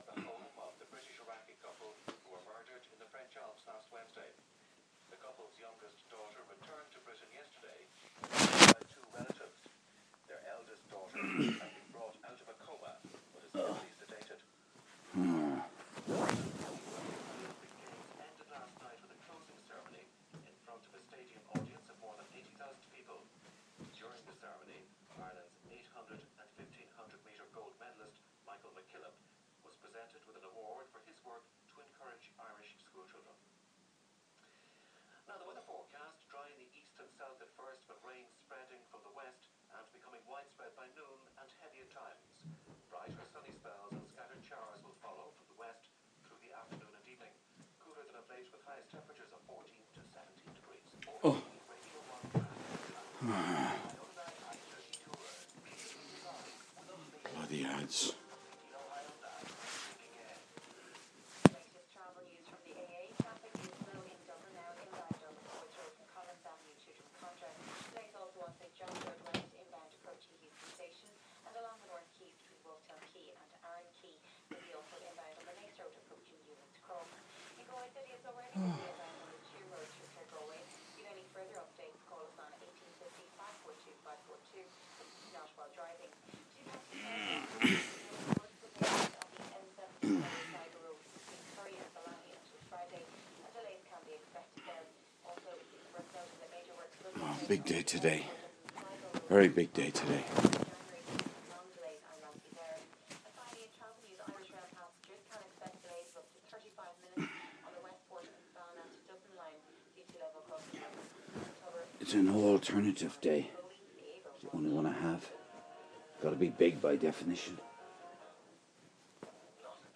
Monday morning (3) - low soundscape (2:11)